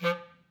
DCClar_stac_F2_v3_rr2_sum.wav